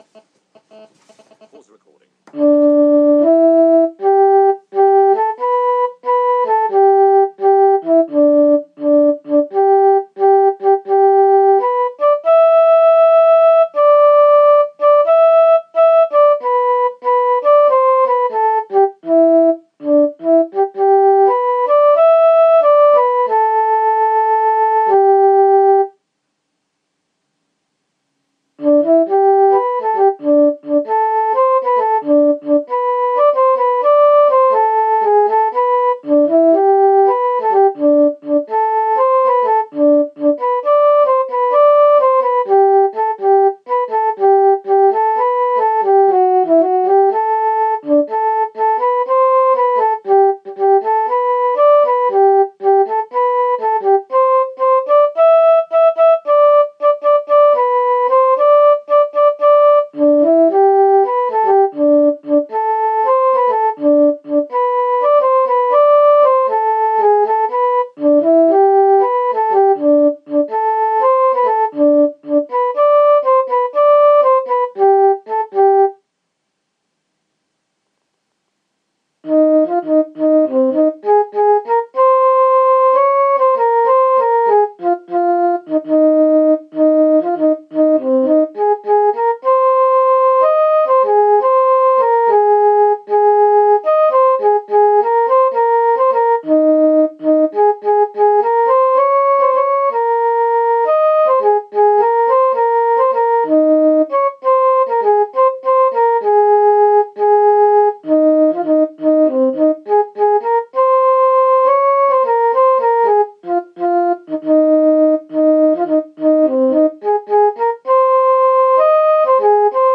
me playing the keyboard